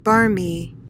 PRONUNCIATION: (BAR-mee) MEANING: adjective: 1.